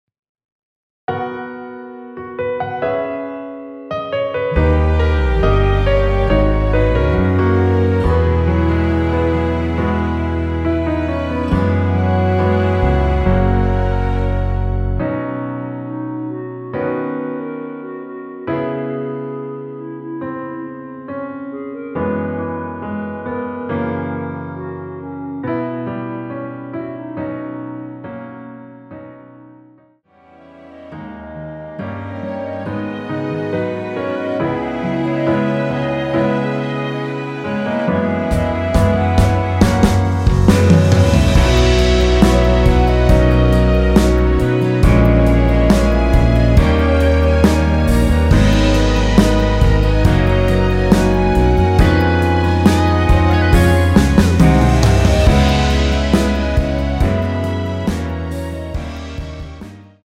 원키에서(-1)내린 (1절+후렴)으로 진행되는 멜로디 포함된 MR입니다.(본문의 가사와 미리듣기 확인)
멜로디 MR이라고 합니다.
앞부분30초, 뒷부분30초씩 편집해서 올려 드리고 있습니다.
중간에 음이 끈어지고 다시 나오는 이유는